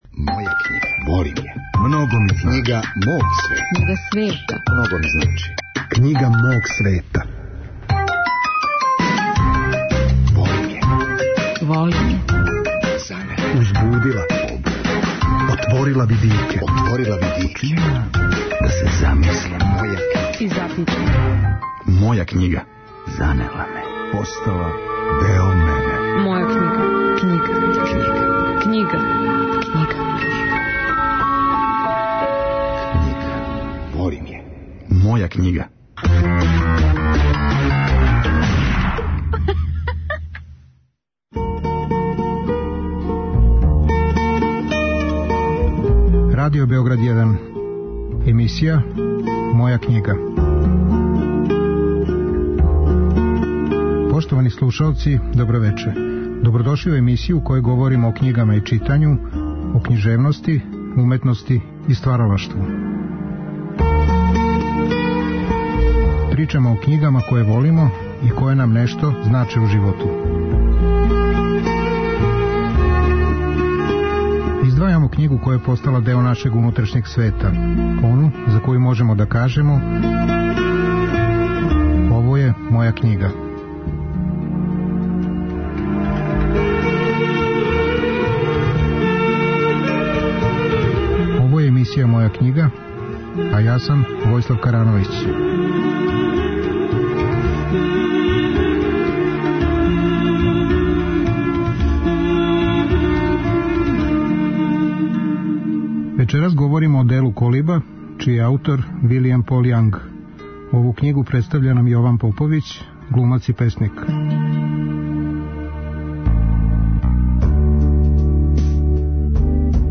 Емисија о књигама и читању, о књижевности, уметности и стваралаштву. Гости су људи различитих интересовања, различитих занимања и професија. Сваки саговорник издваја књигу коју воли, ону која му је посебно значајна и за коју може да каже: ово је моја књига.